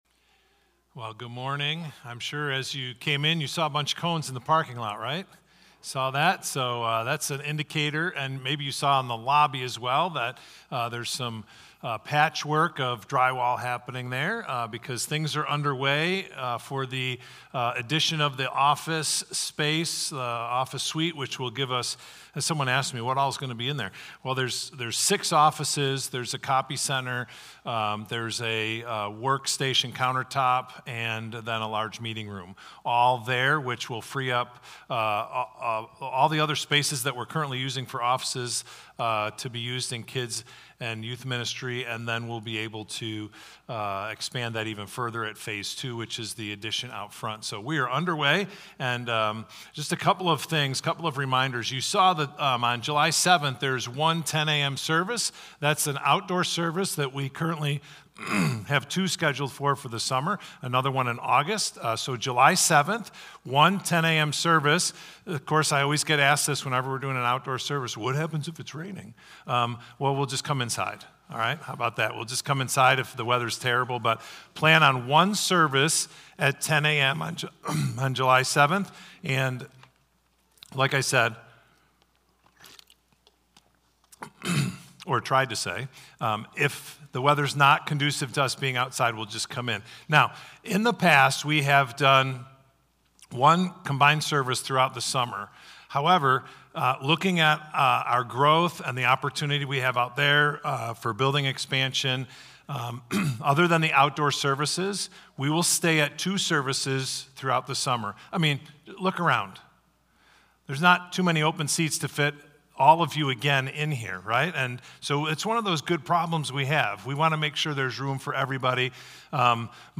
Victor Community Church Sunday Messages / What is God's Purpose for Me?